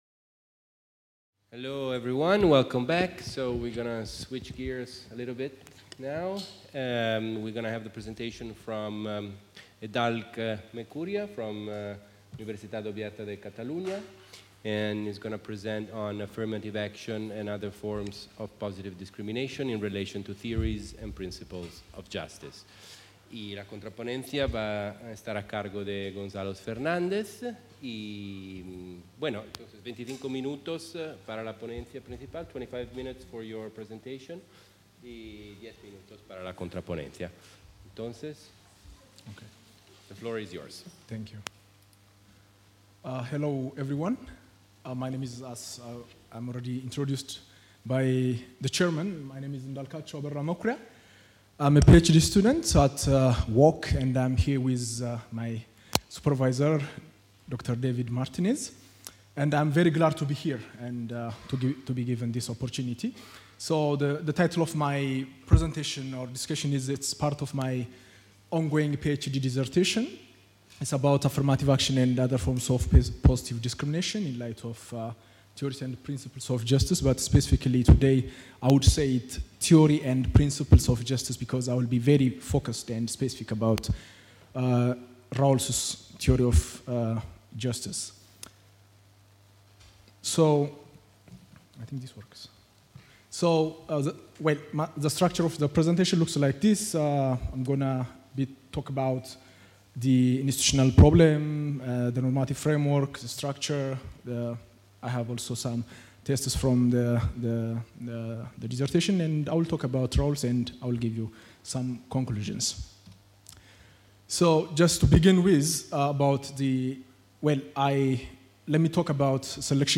The Chair of Legal Culture of the UdG organizes the II Catalan Interuniversity Seminar on Philosophy of Law.